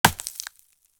Bear Sound Effects - Free AI Generator & Downloads
ice-bear-bellow-ice-crack-2bi5xxvi.wav